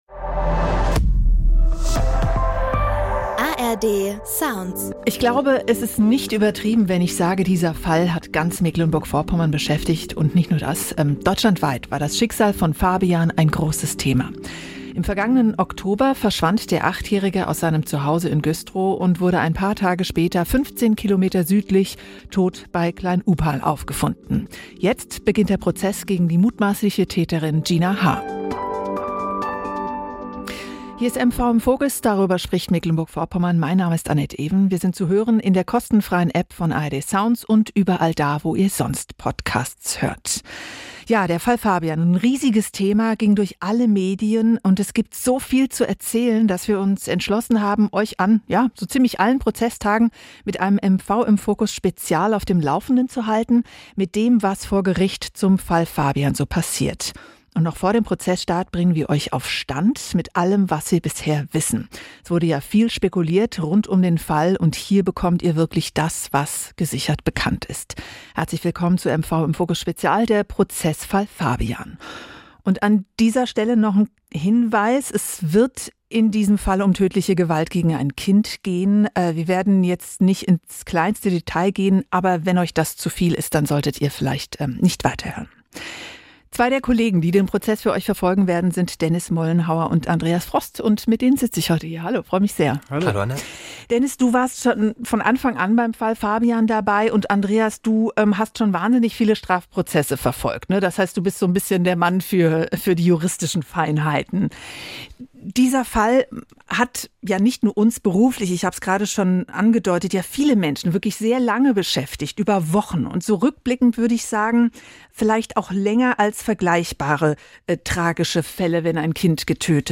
Zum Auftakt rollen die Reporter die wichtigsten Punkte zum Hergang und zu den Ermittlungen auf und erklären was nun bei Gericht passiert.